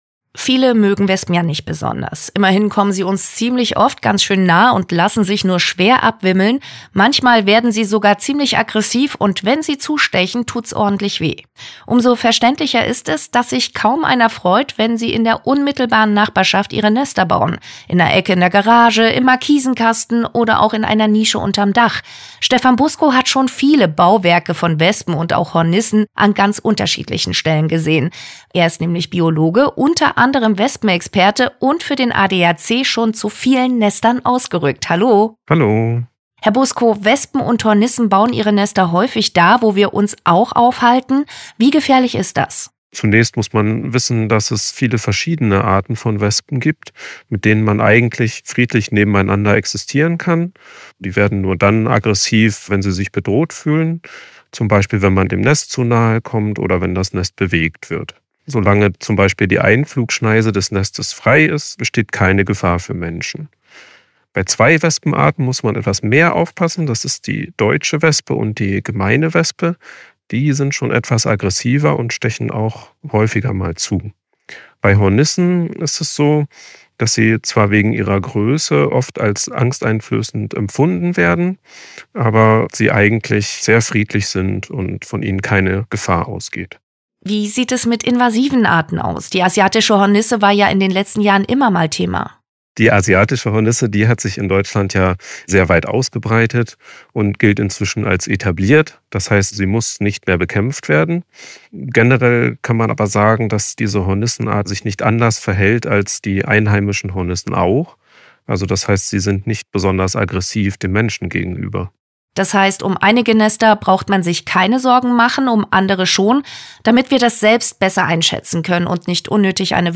Einer unserer Wespennestentferner erzählt